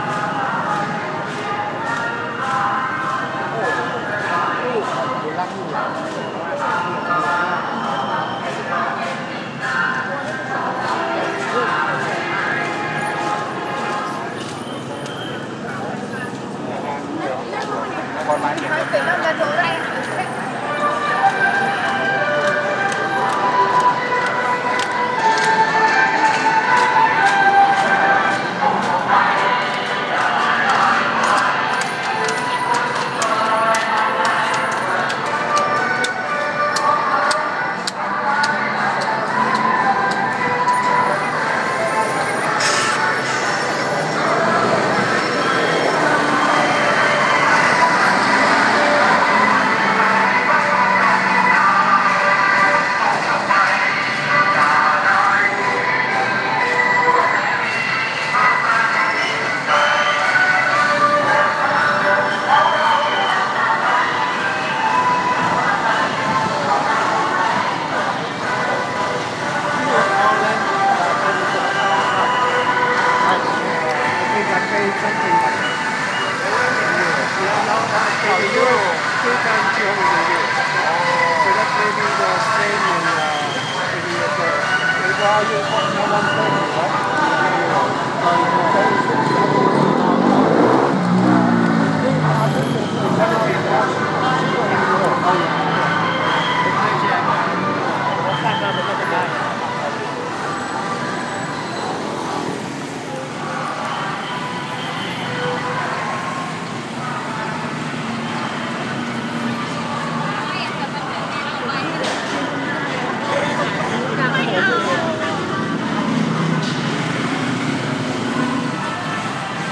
Manif devant les quartiers de la police, Grungthep